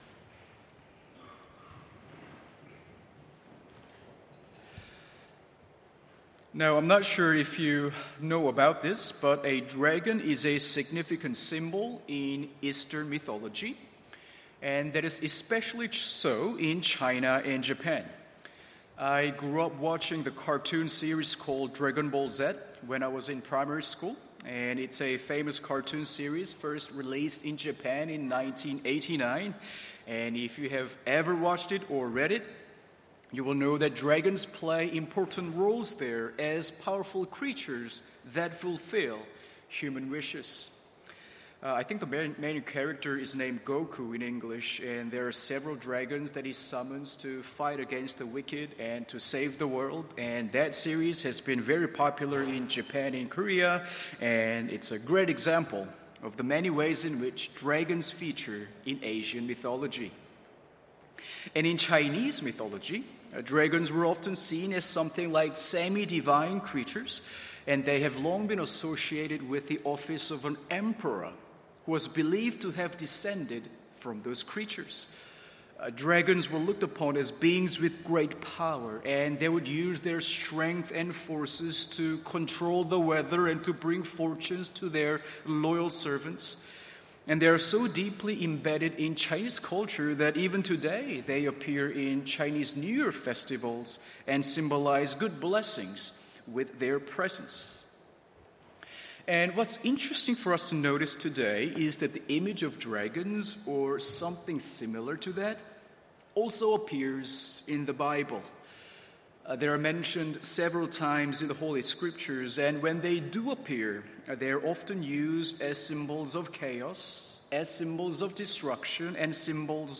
MORNING SERVICE Exodus 14:24-31; Revelation 13:1-10…